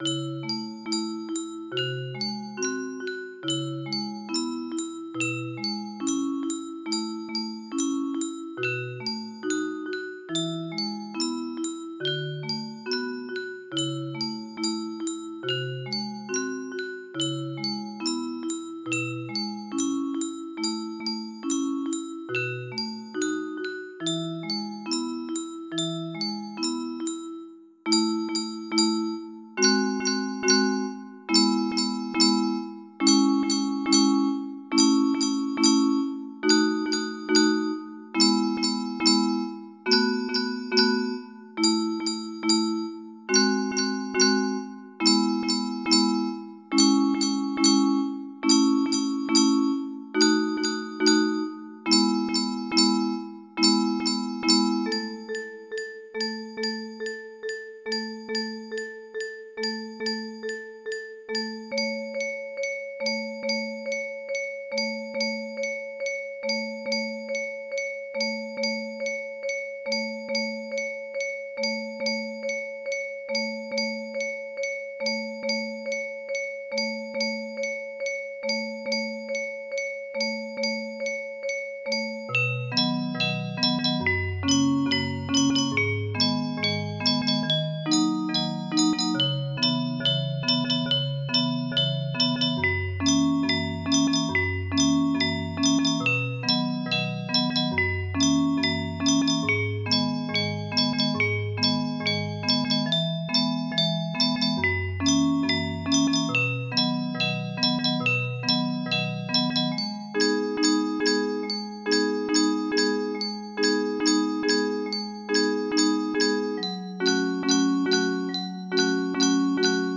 くつろぎ